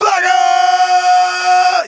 Tm8_Chant15.wav